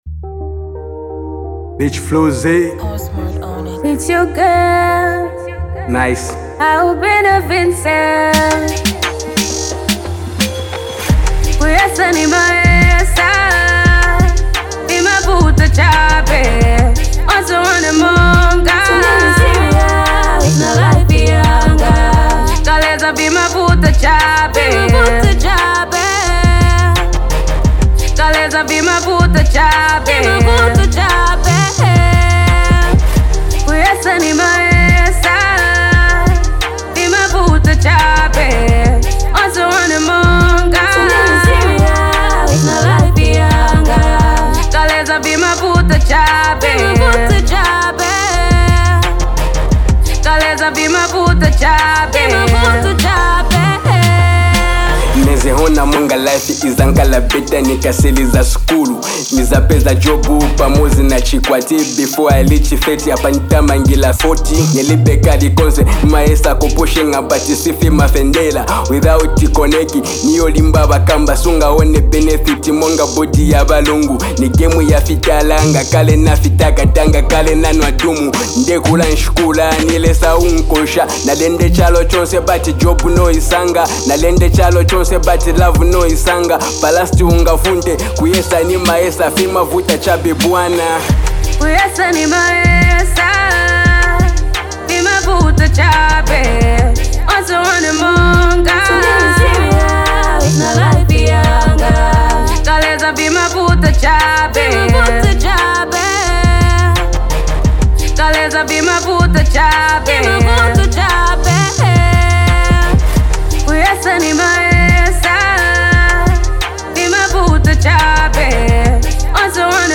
resonant new song
an up-and-coming female Zambian artist.